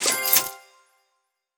Item Purchase (4).wav